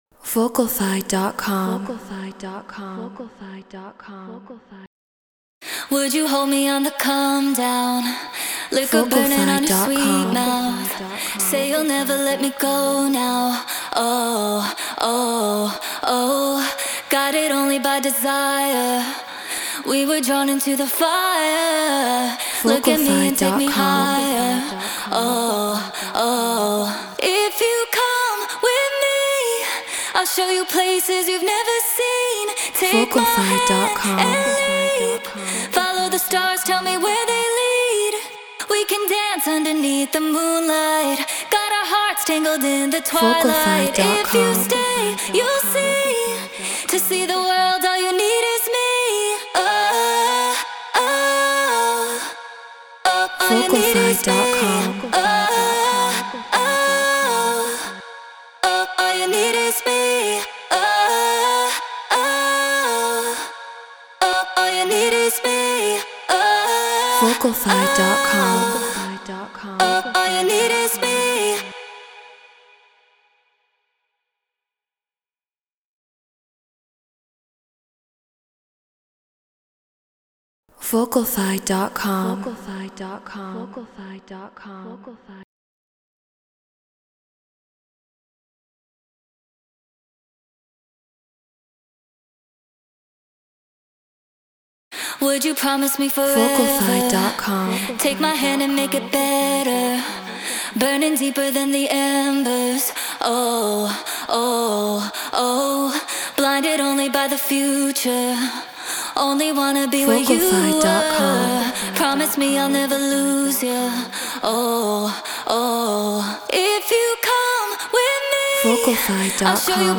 Drum & Bass 172 BPM G#min
Flea 47 Apogee Symphony Mark ii Logic Pro Treated Room